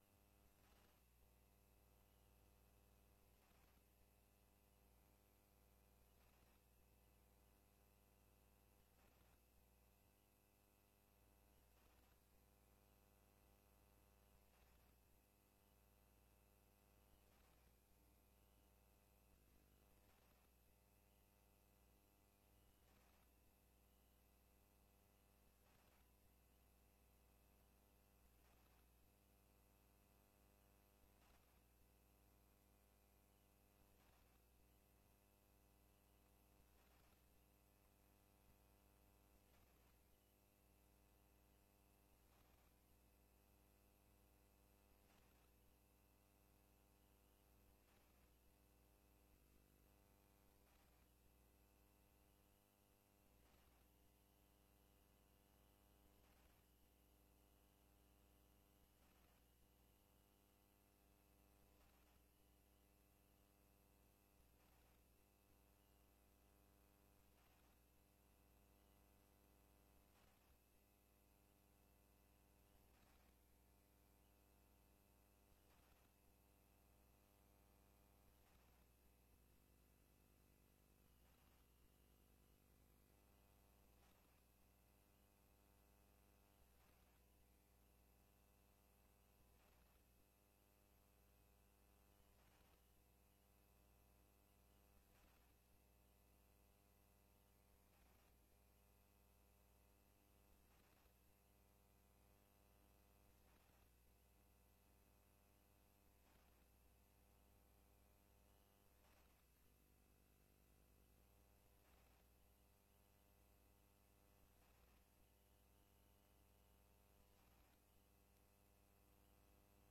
Openbare technische sessie zonder inspraak over ombuigingen, heroverwegingen voor de BUCH gemeenten en Bergen specifiek.
Locatie: Raadzaal